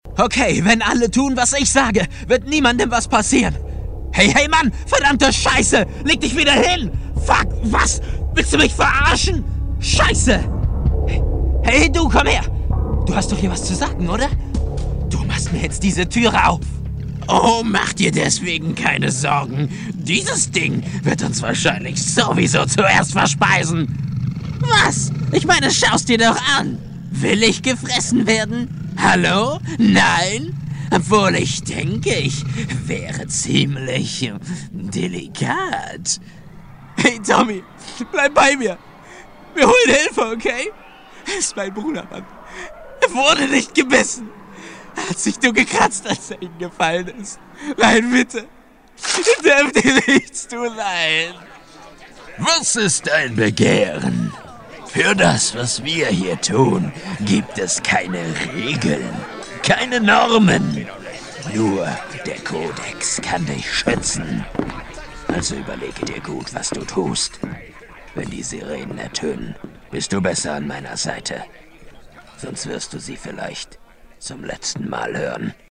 德语中年大气浑厚磁性 、沉稳 、神秘性感 、素人 、男宣传片 、绘本故事 、动漫动画游戏影视 、600元/百单词男德103 德语男声 干音 大气浑厚磁性|沉稳|神秘性感|素人